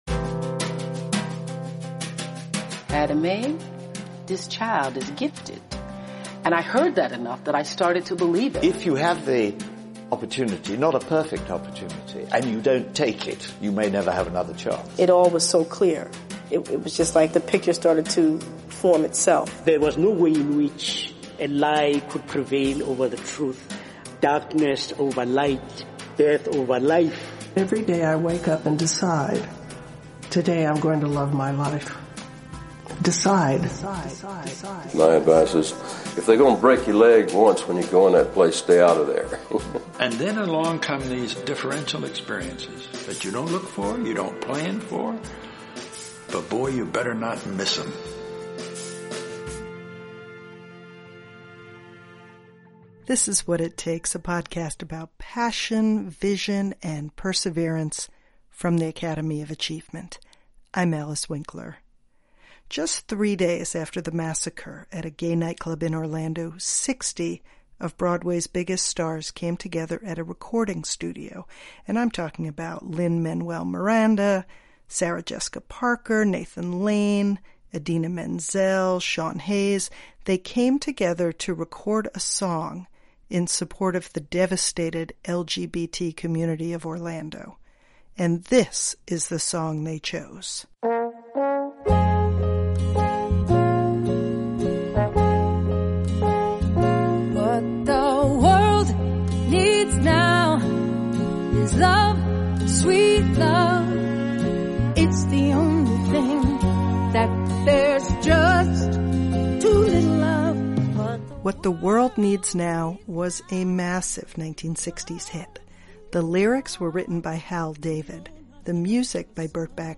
So here is the reason we’re combining Carole King with Hal David in one episode. In their interviews with the Academy of Achievement, each talked about the art of the song.